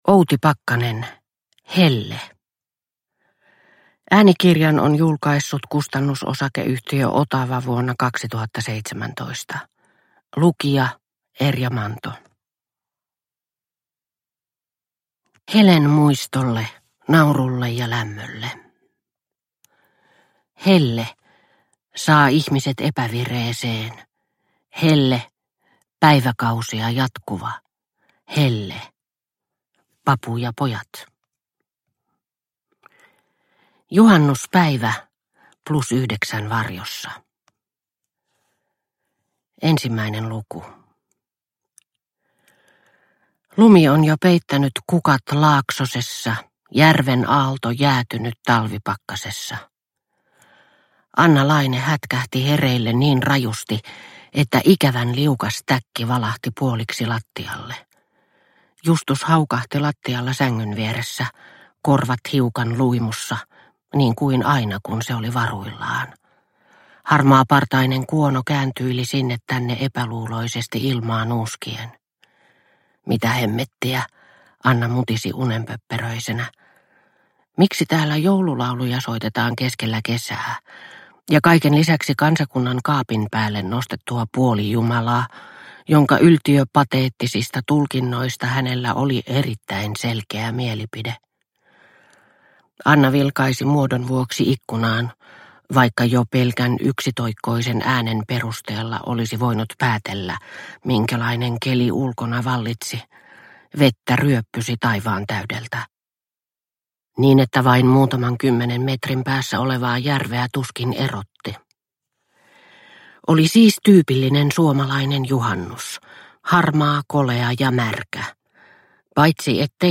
Helle – Ljudbok – Laddas ner